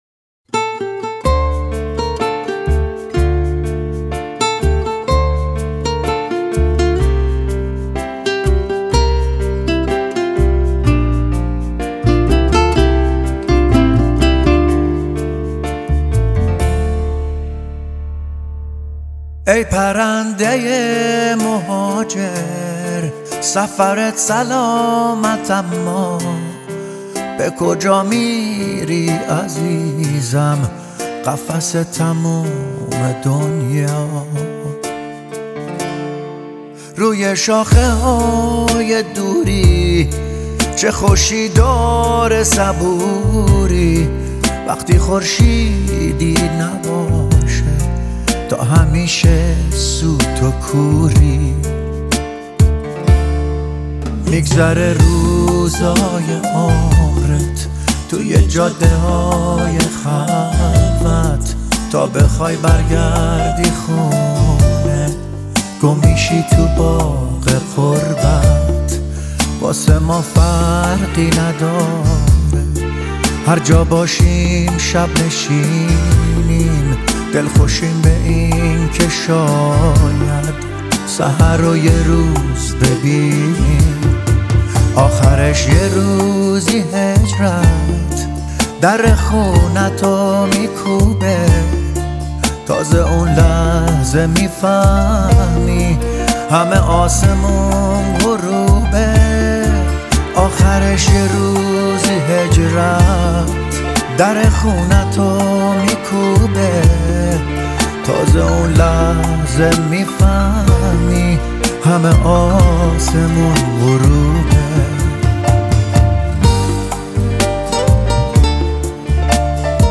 آهنگ قدیمی و عاشقانه